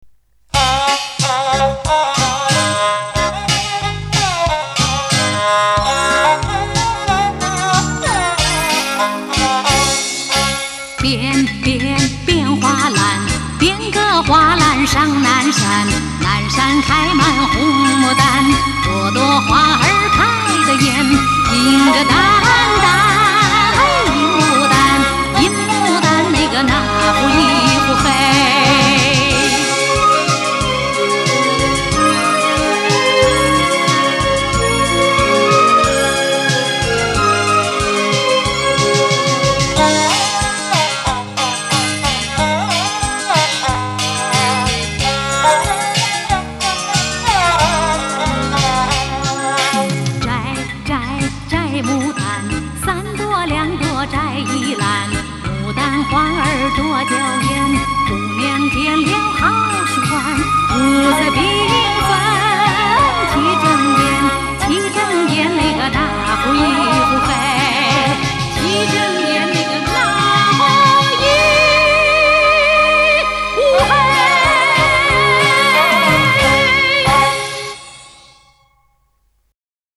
大型电视歌舞艺术片